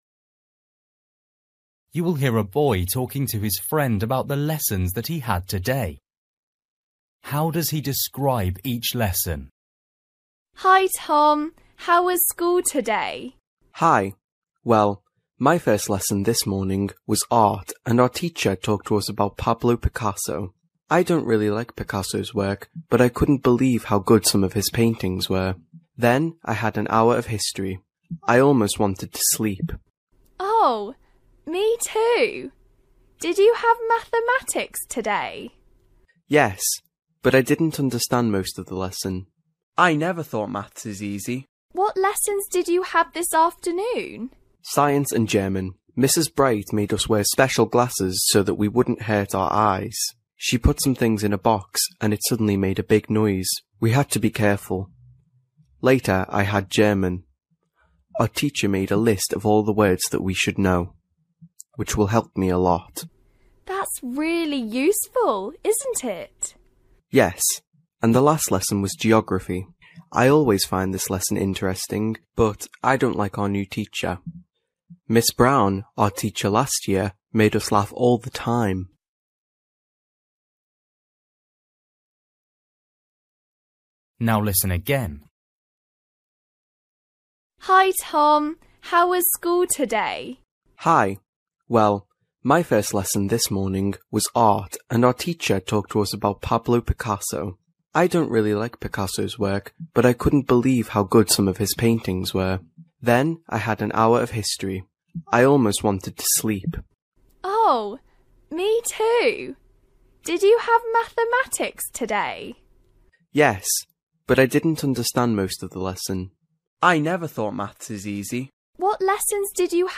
You will hear a boy talking to his friend about the lessons that he had today.